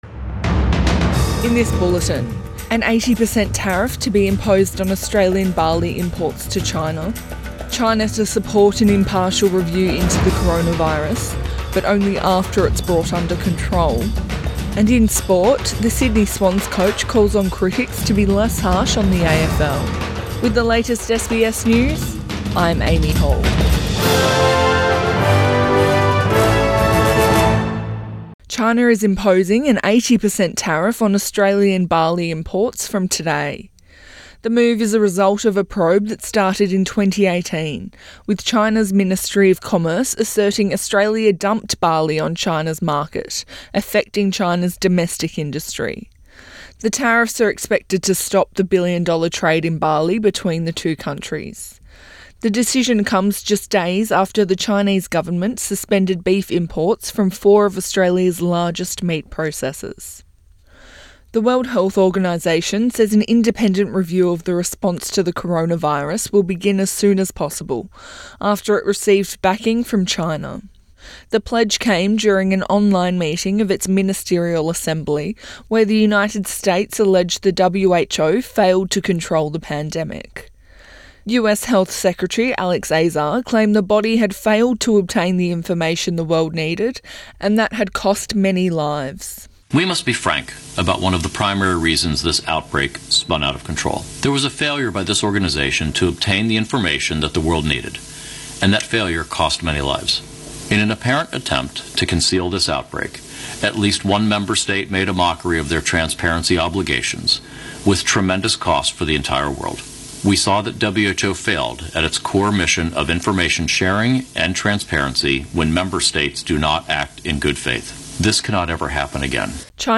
AM bulletin 19 May 2020